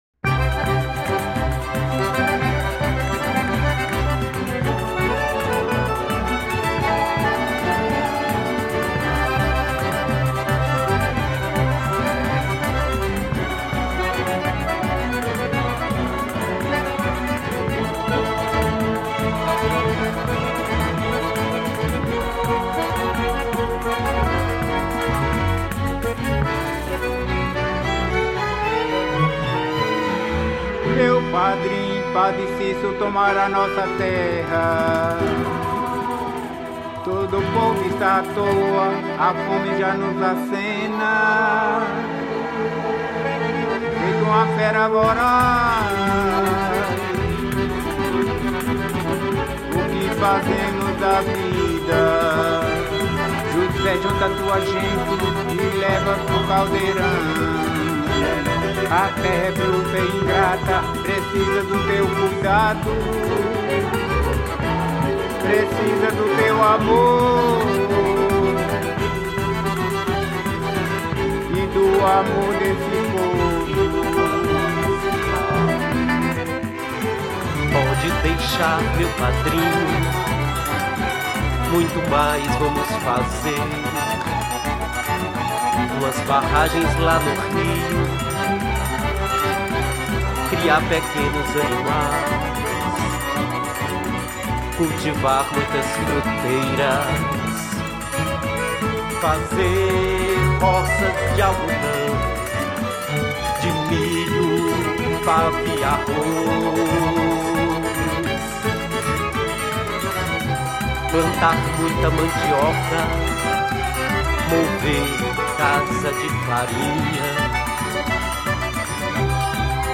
Gênero: Regional